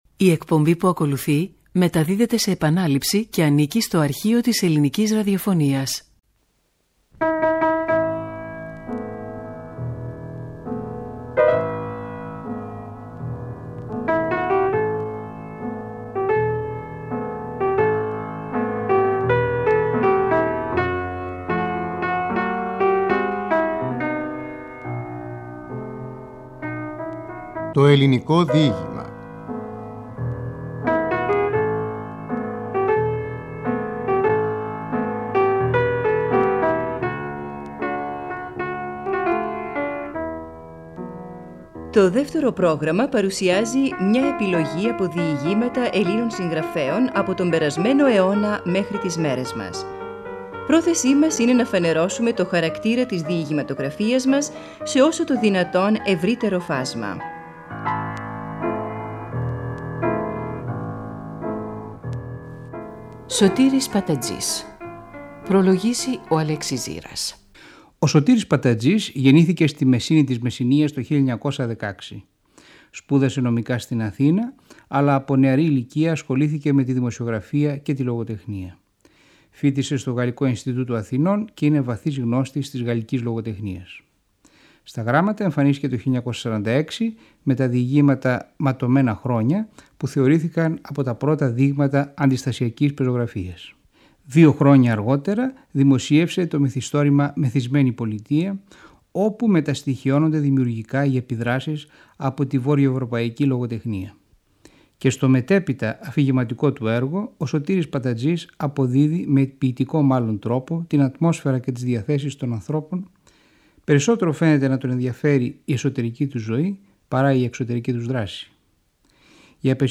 Ο Σ τέφανος Ληναίος διαβάζει το έργο «Θάλαμος Σιωπής» .